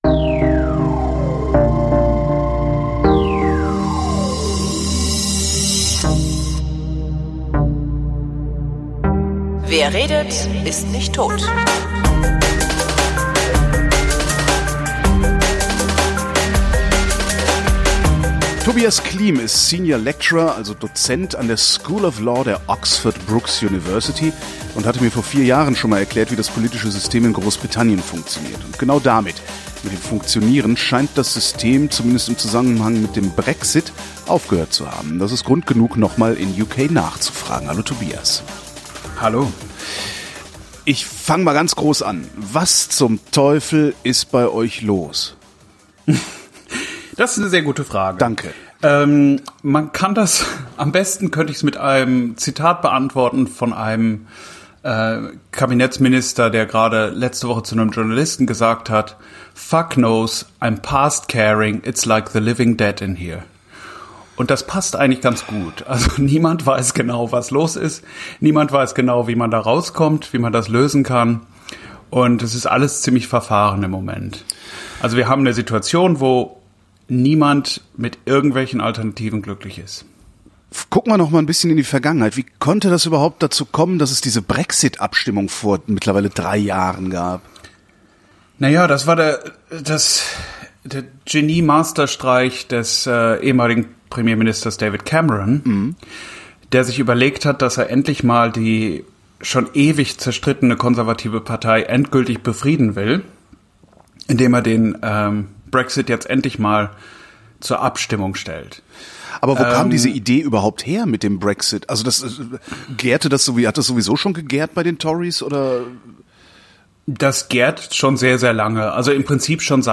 wrint: gespräche zum runterladen